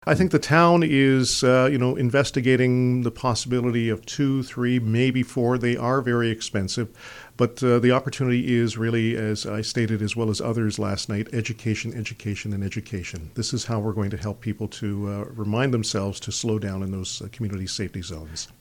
Orangeville Councilor,  Joe Andrews, explains the rationale behind the use of the cameras:
joe-andrews-speed-cameras.mp3